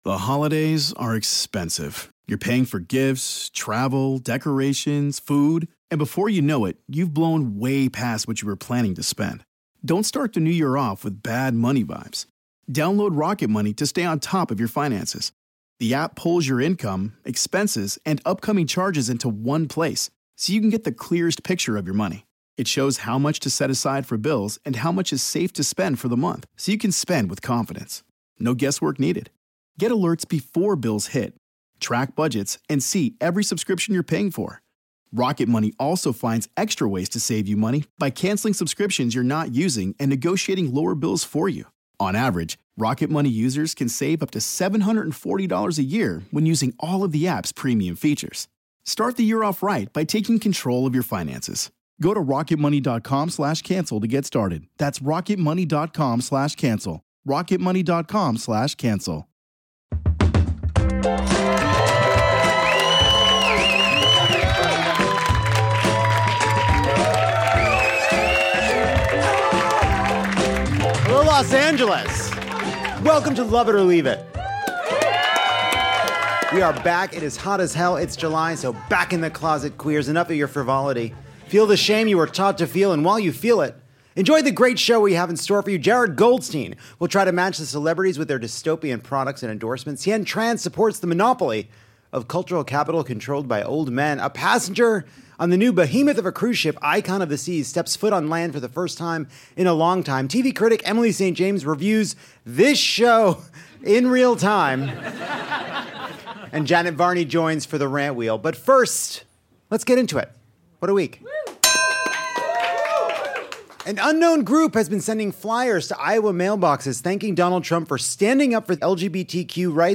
All aboard a brand-new episode of Lovett or Leave It, setting sail from Los Angeles’s beautiful Dynasty Typewriter theater.